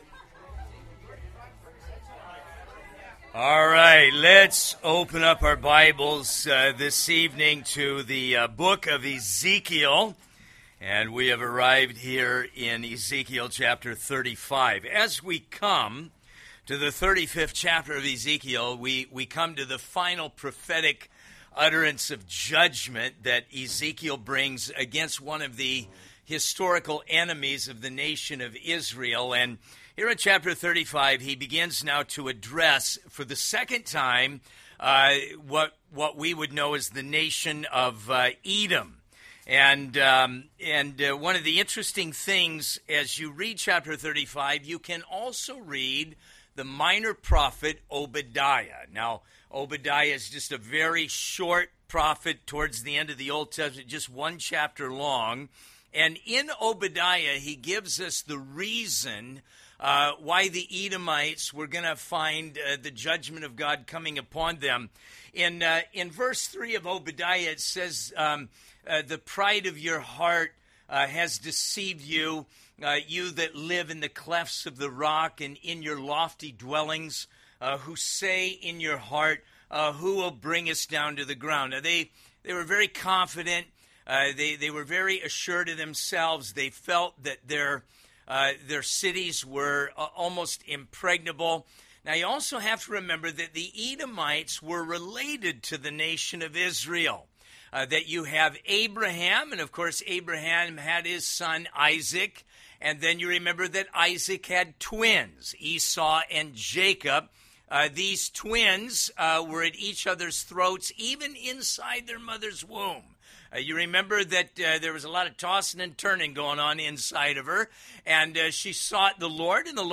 A study in Ezekiel 35-36 from Harvest Fellowship’s Midweek Service.